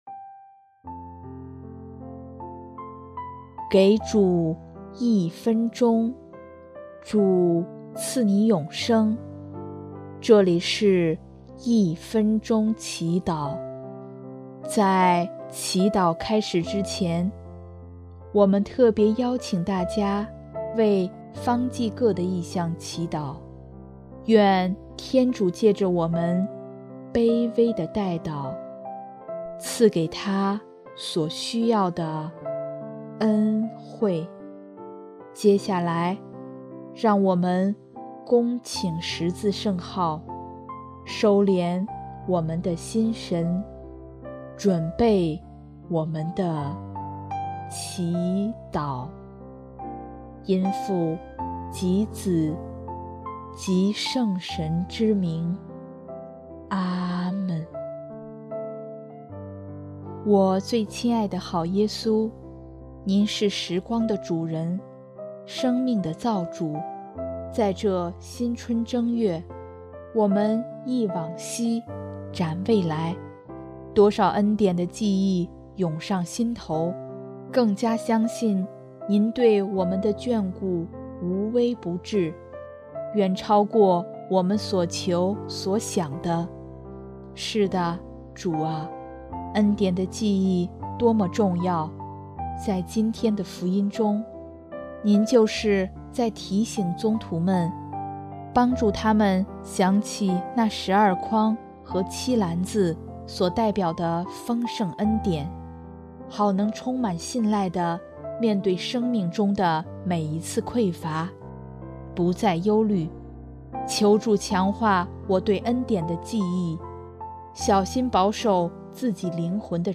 音乐： 第四届华语圣歌大赛优秀奖《十架上》（方济各：为处在迷茫焦虑的人祈祷，希望天主能引导带领他们，找到前进的方向）